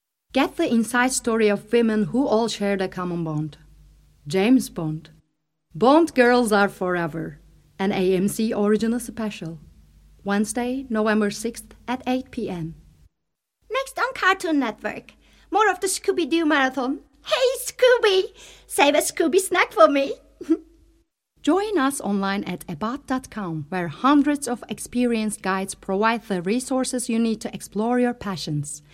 Middle Eastern, Turkish, Female, 20s-30s